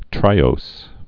(trīōs)